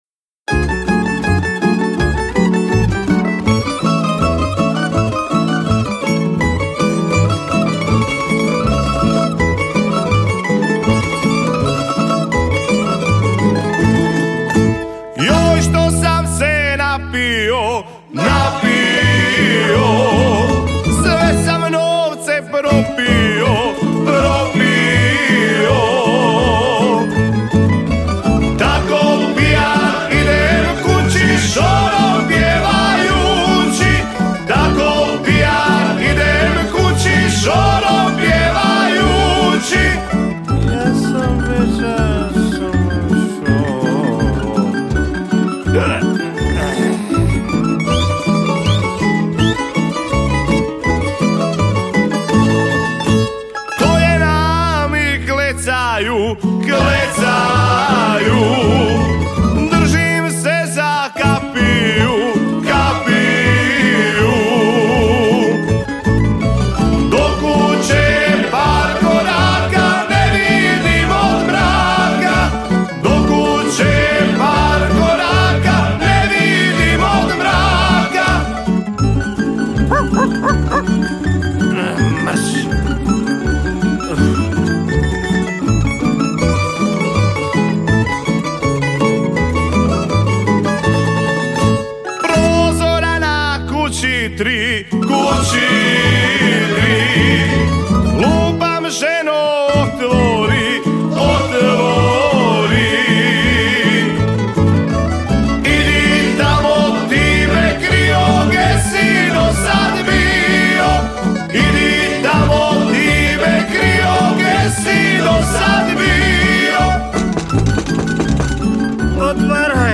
Na festivalu je ove godine nastupilo 19 izvođača s novim autorskim pjesmama, od toga je bilo 8 tamburaških sastava te 11 solista.